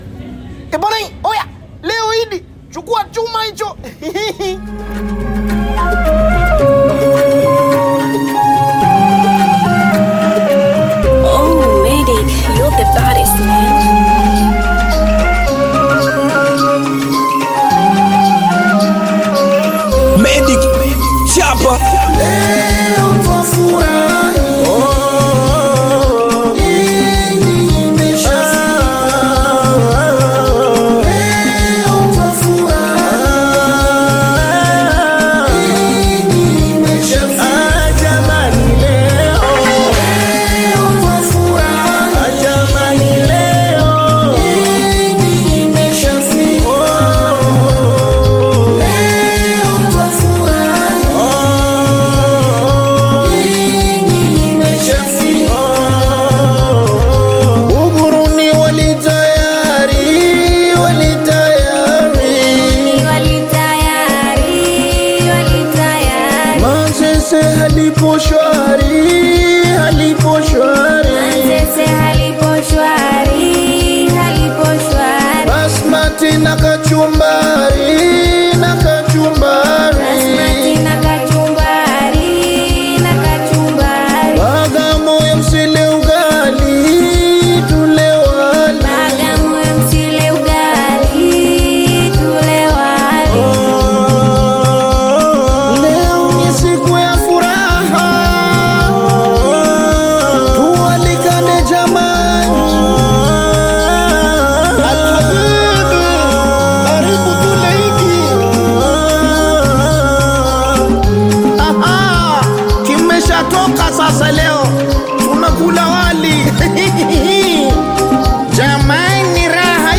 Singeli and Bongo Flava
African Music